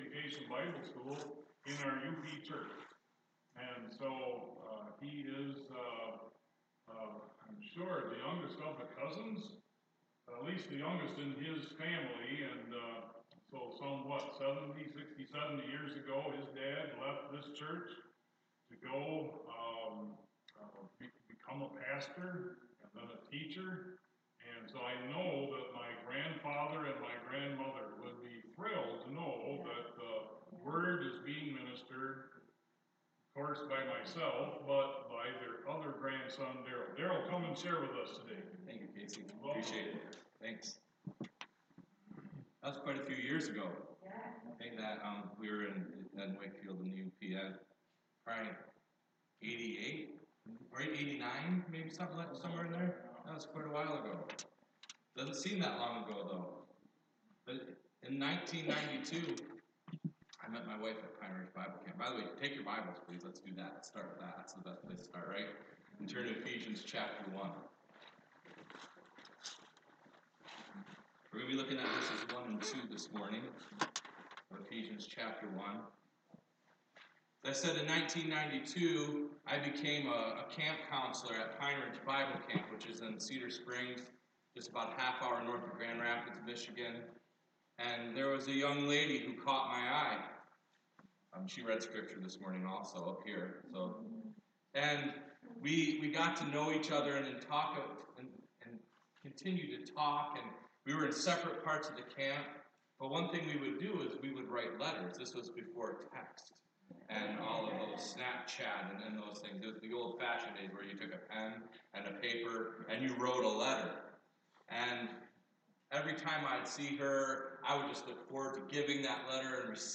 Sunday Morning Messages Sunday Morning Message Guest Speaker